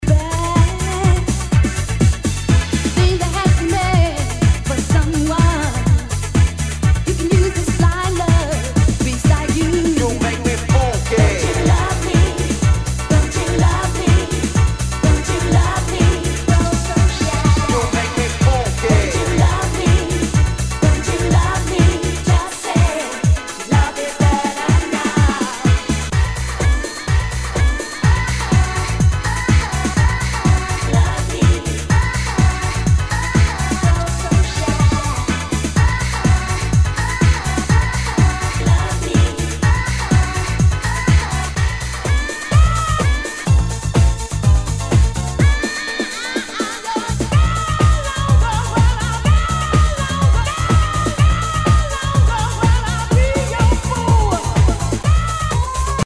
HOUSE/TECHNO/ELECTRO
ナイス！ユーロ・ヴォーカル・ハウス・クラシック！
全体にチリノイズが入ります。